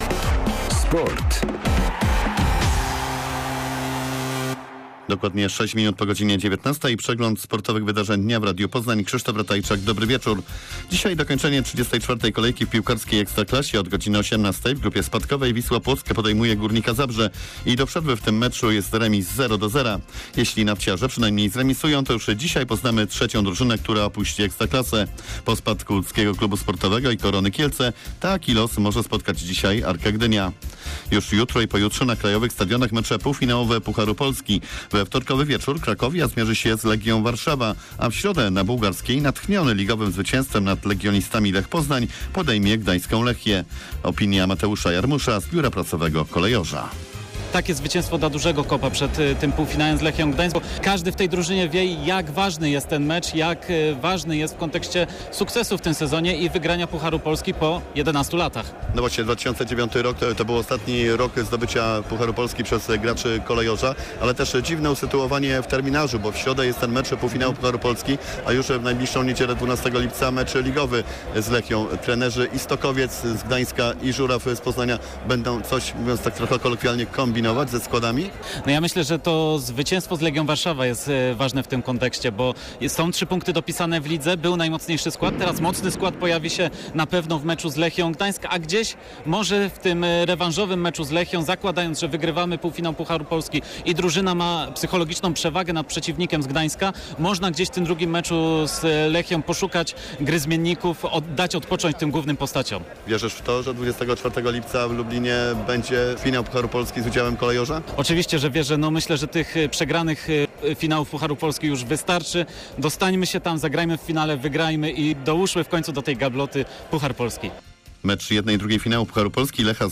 serwis sportowy